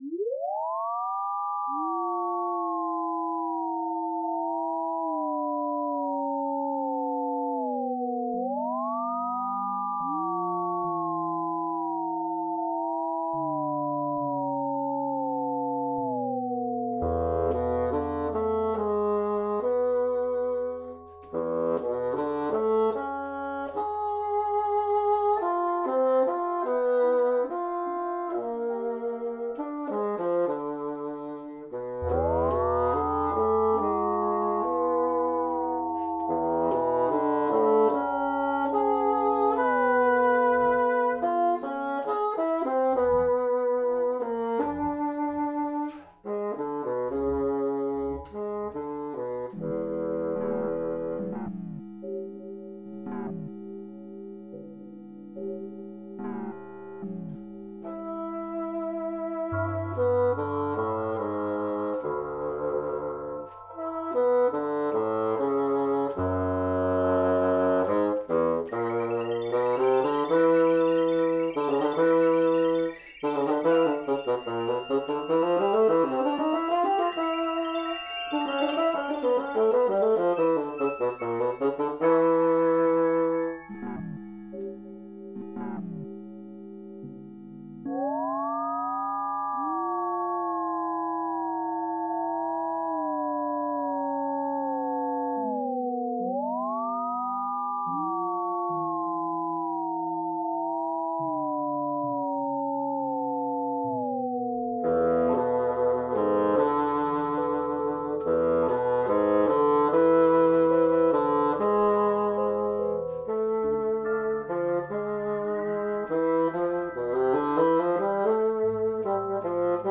alternate version: Bassoon & Electronics
summer2024electronic.mp3